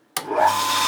pump_start.wav